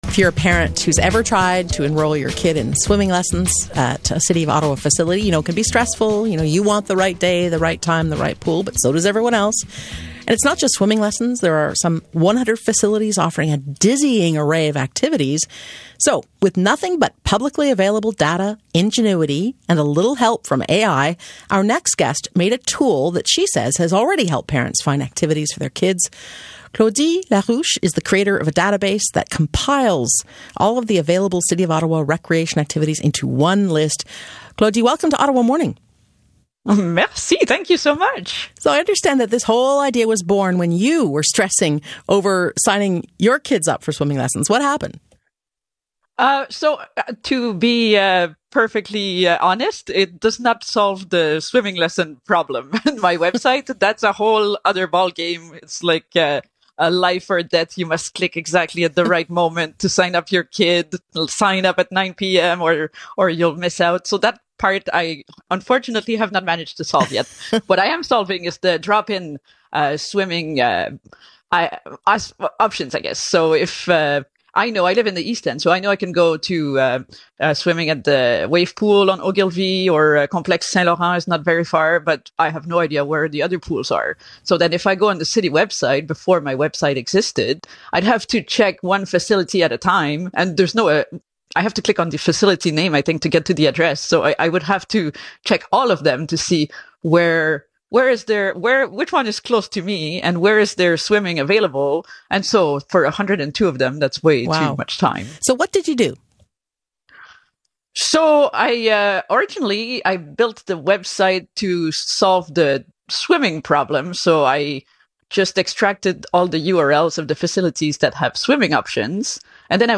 🎙 CBC Ottawa Morning – Live Interview
Type: Radio interview